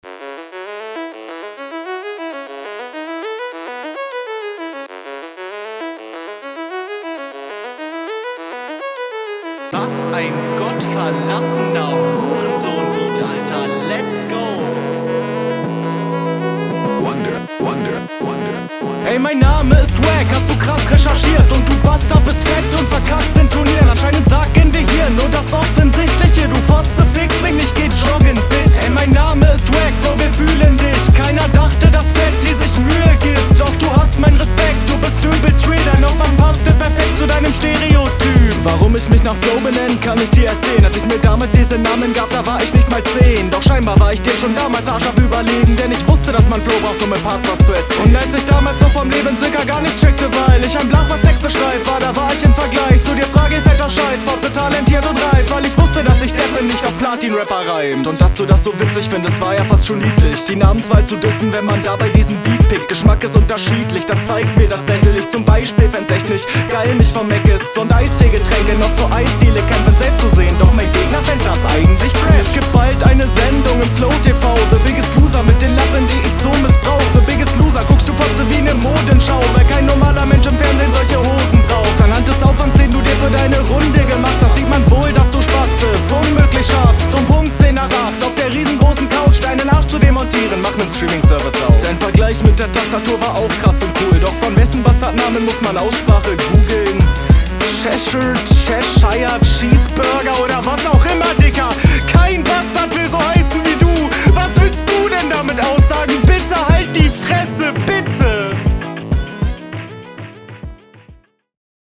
Besser verständlich, obwohl der Beat meiner Meinung nach auch Katastrophe ist.
Echt gute Technik und der Flow geht auch gut nach vorne.